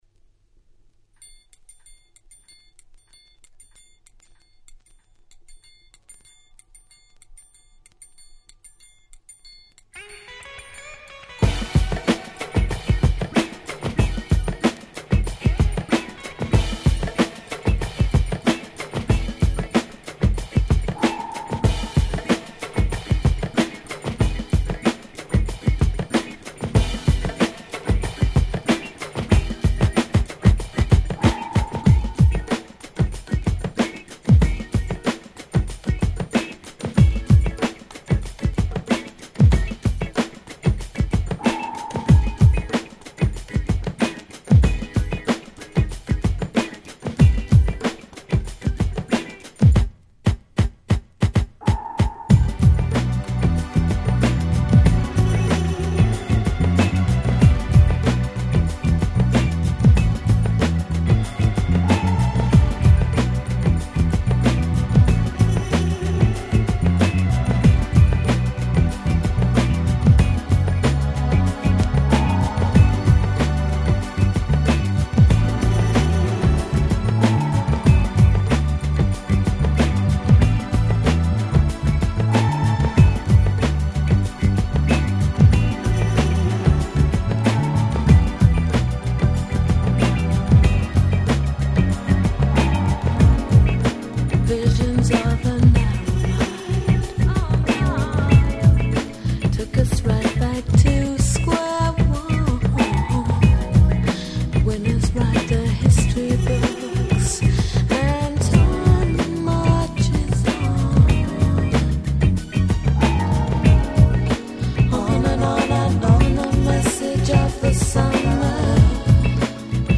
93' UK Soul Classics !!
洗練されたMelodyと彼女の淡い歌声が絶妙過ぎて堪りません！